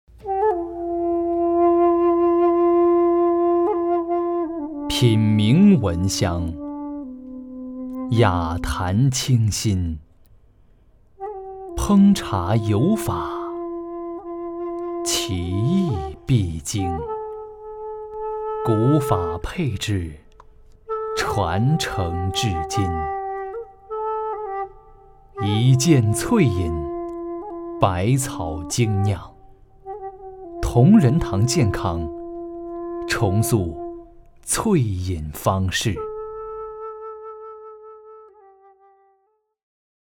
【广告】同仁堂